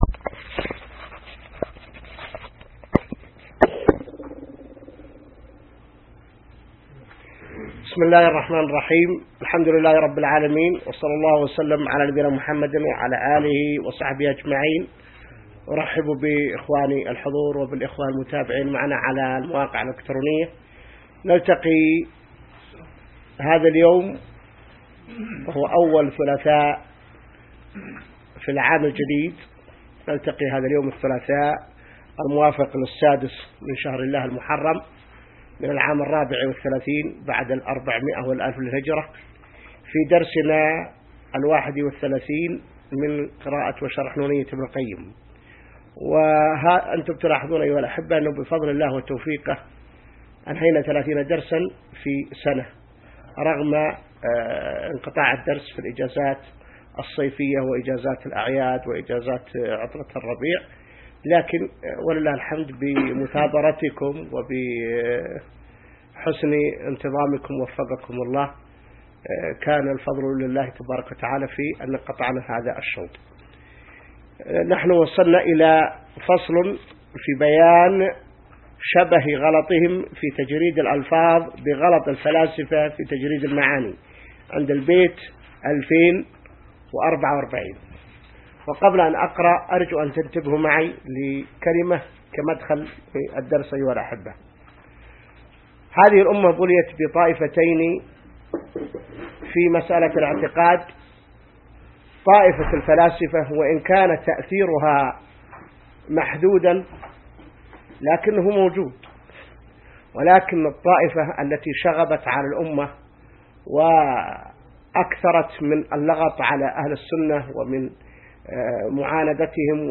الدرس 31 من شرح نونية ابن القيم | موقع المسلم